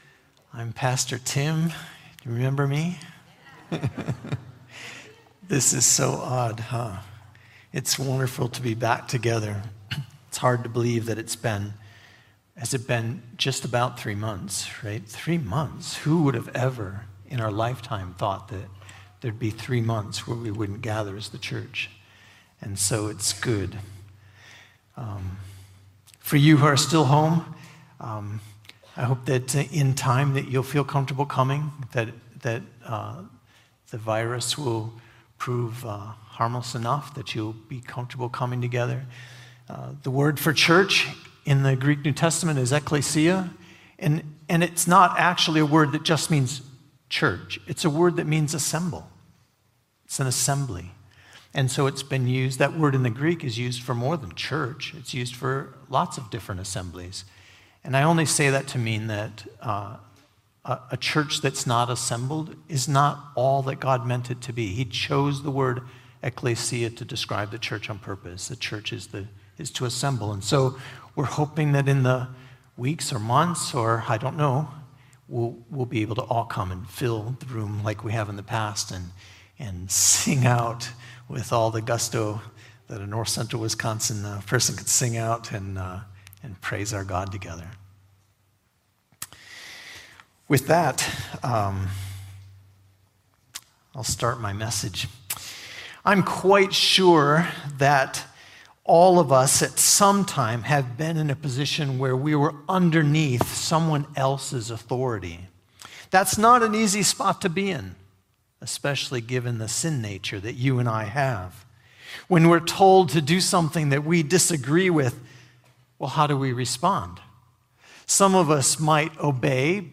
First-Baptist-Sermon-June-7-2020.mp3